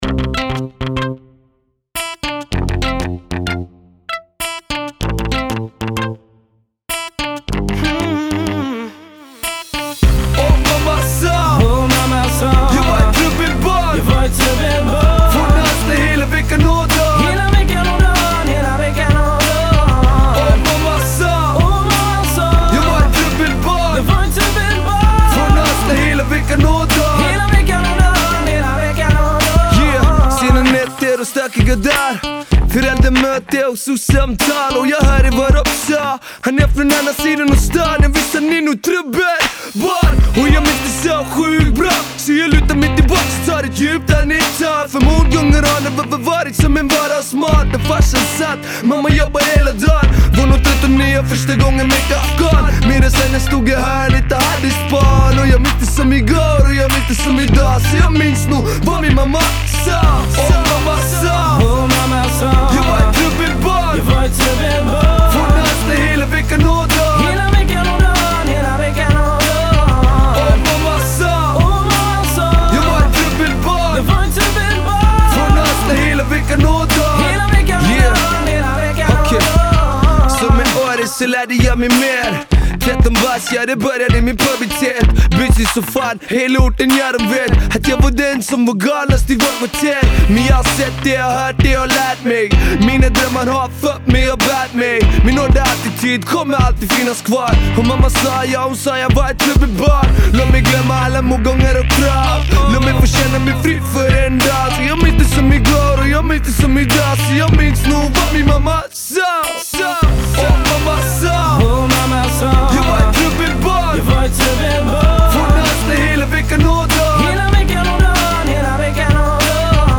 Riktigt bra, han har verkligen en skön röst att lyssna på.